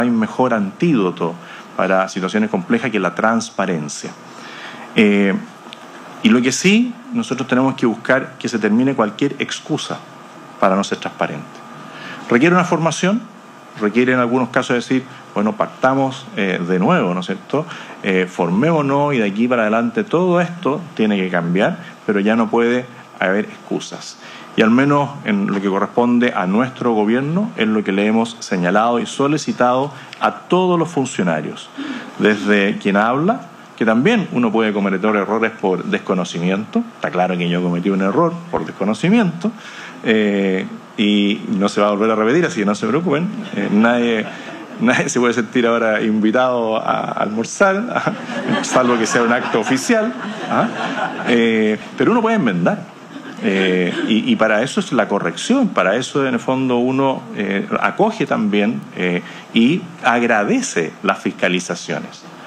El Presidente José Antonio Kast participó este viernes en un seminario del Consejo para la Transparencia, instancia en la que reafirmó el compromiso de su gobierno con la transparencia y el cumplimiento de estándares en la función pública.
Las declaraciones se producen luego del oficio de la Contraloría por un almuerzo realizado en La Moneda con excompañeros de universidad, hecho al que el Presidente aludió con un tono distendido durante su discurso.
“Nadie se puede sentir ahora invitado a almorzar, salvo que sea un acto oficial”, comentó, generando risas entre los asistentes.
El-presidente-Jose-Antonio-Kast-participo-en-una-jornada-enfocada-en-la-transparencia-en-las-ins.mp3